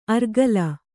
♪ argala